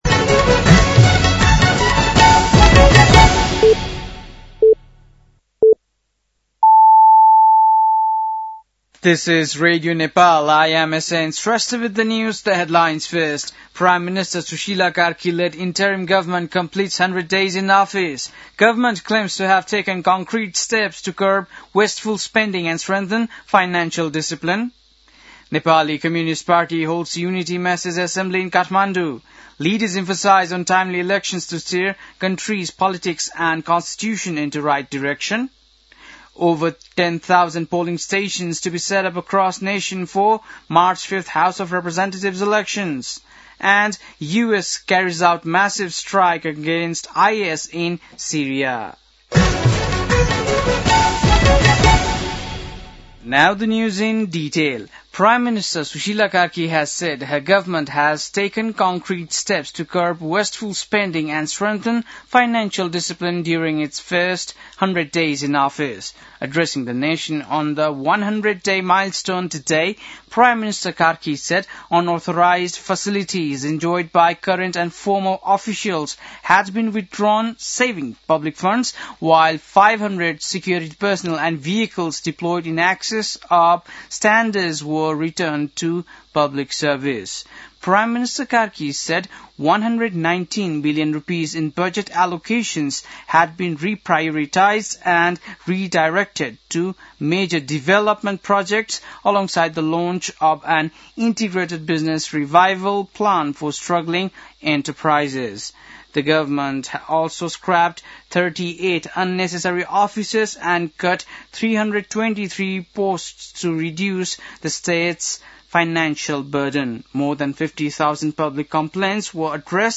बेलुकी ८ बजेको अङ्ग्रेजी समाचार : ५ पुष , २०८२